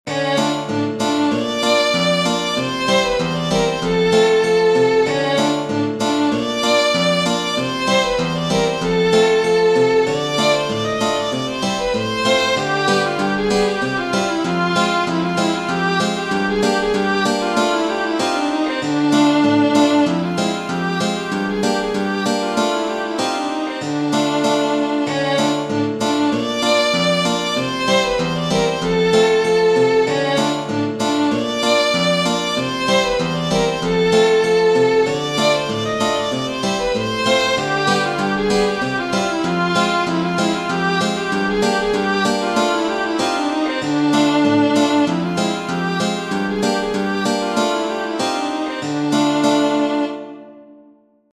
Tradizionale Genere: Folk La hora o horo è una musica e una danza collettiva tradizionale , tipica del folklore dei Balcani , della Romania , della Moldavia ma anche di Israele.